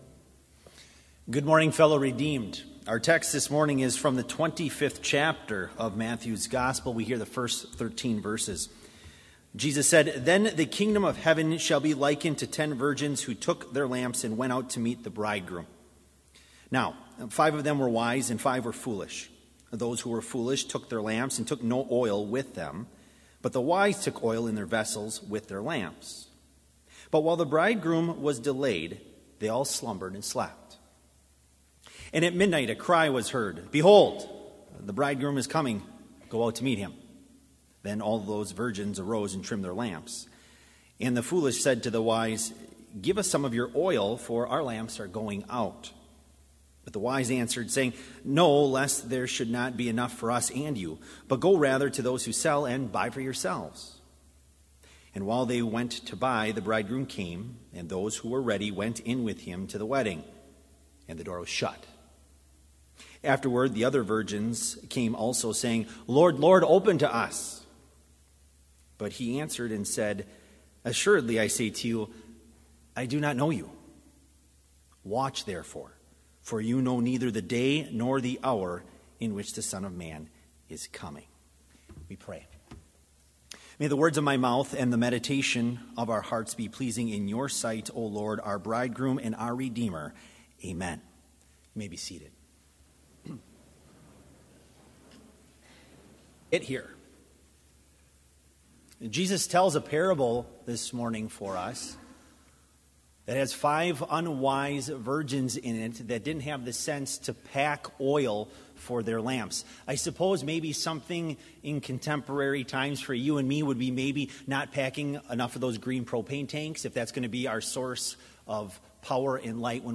Complete service audio for Chapel - November 30, 2018